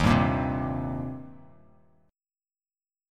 Eb6 Chord
Listen to Eb6 strummed